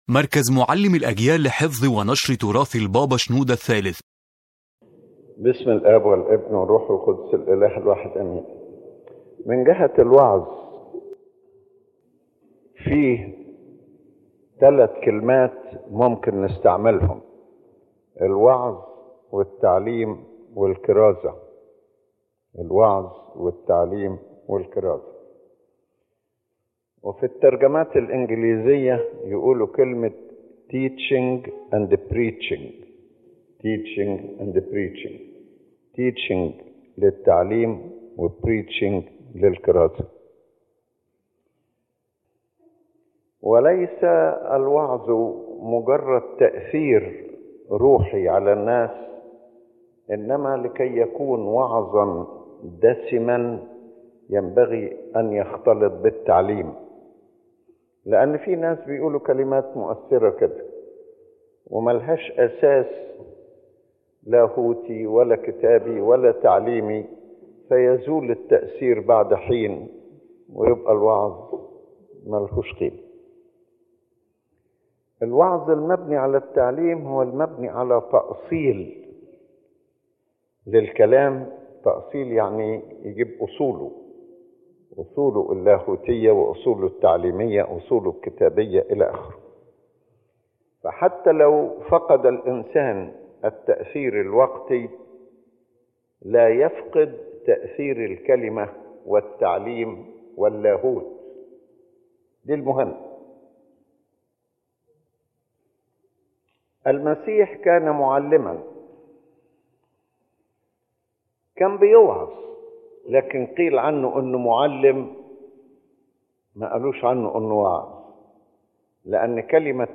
The lecture explains the deep relationship between preaching, teaching, and evangelism in church ministry. It emphasizes that true preaching is not merely emotional or influential words, but must be based on solid biblical and theological teaching.